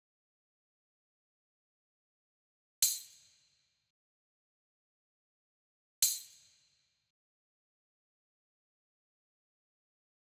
Beast_Shaker.wav